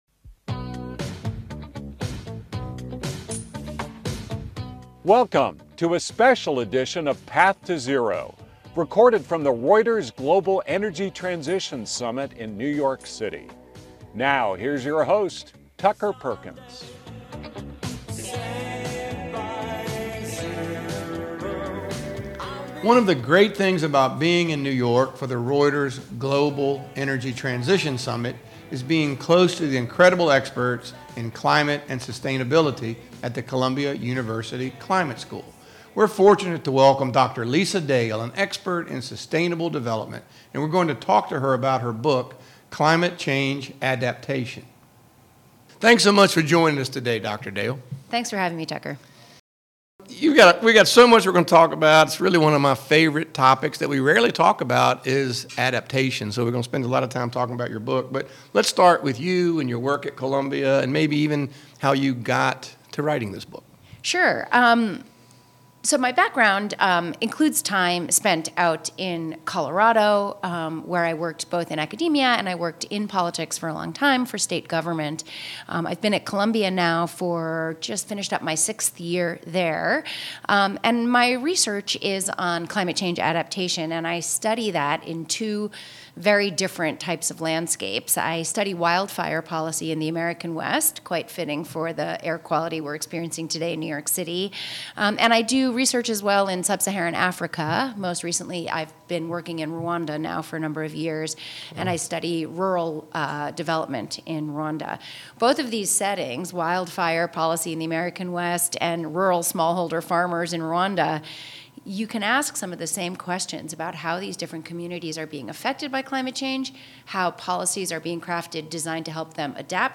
recorded from the Reuters Global Energy Transition Summit in New York